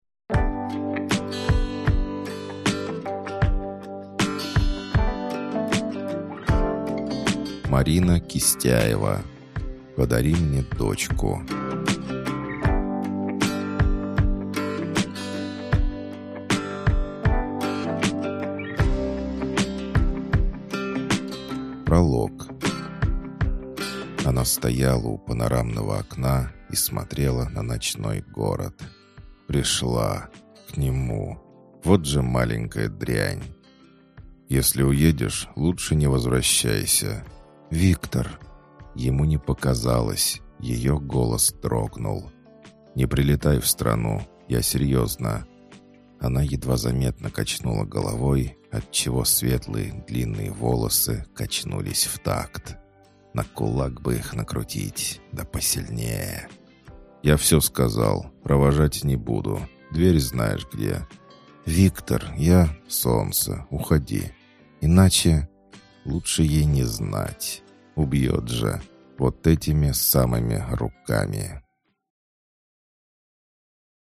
Аудиокнига Подари мне дочку | Библиотека аудиокниг